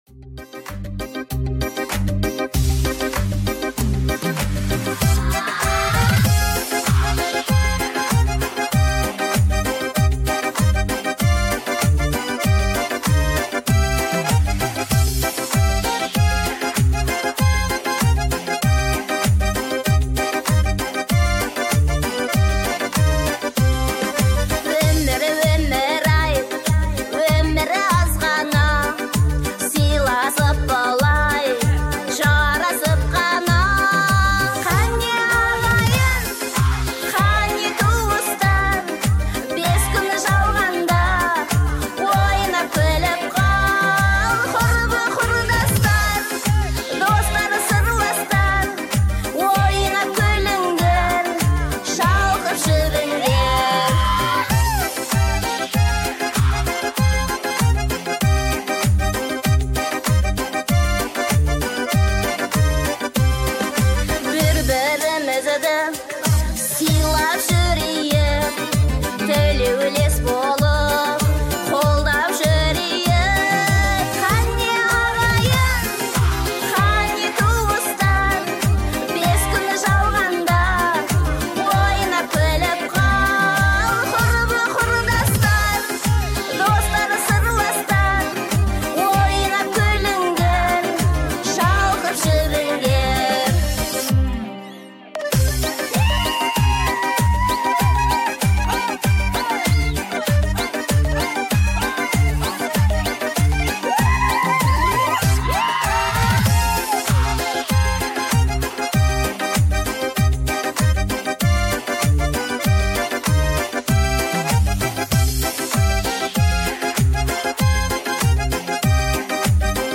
Нацмены поют что-то весёлое, помогите узнать ...